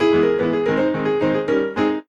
Add piano sound (CC0)
sounds_piano.ogg